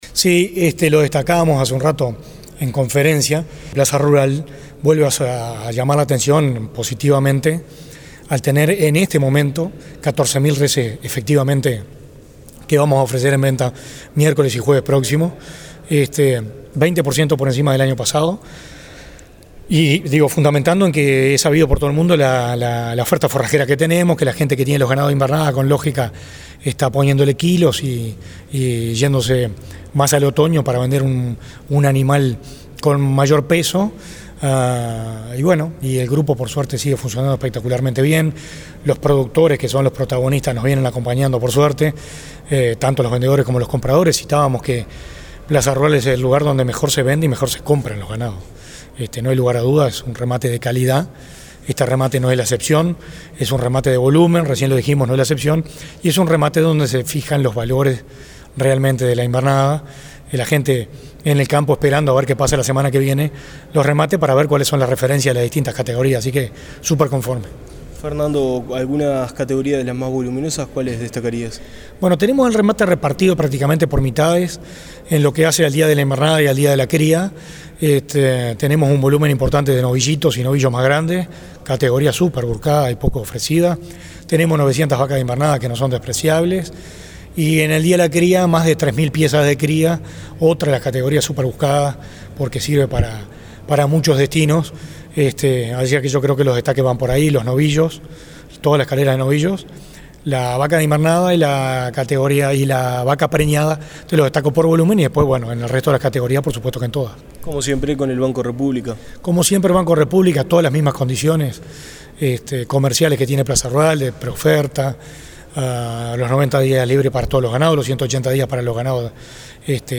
En entrevista con Dinámica Rural